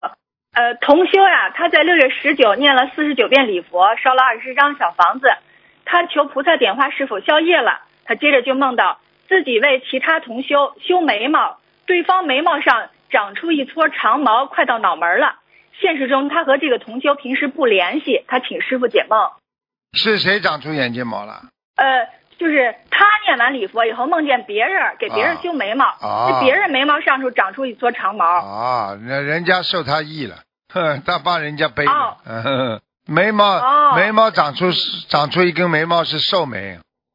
目录：☞ 剪辑电台节目录音_集锦